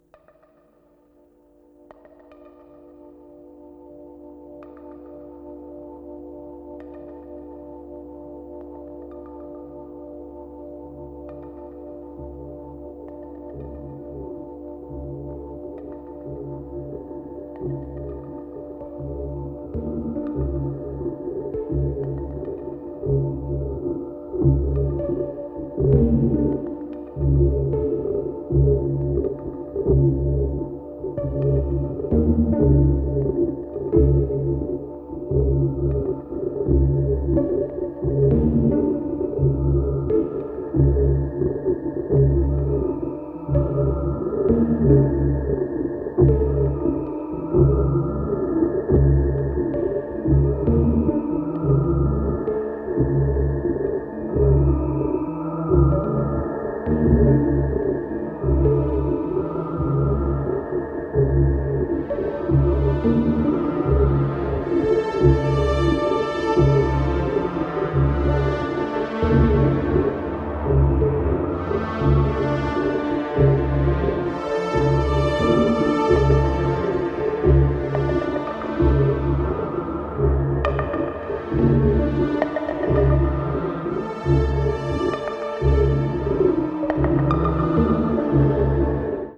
Wave Ambient